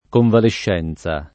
[ konvalešš $ n Z a ]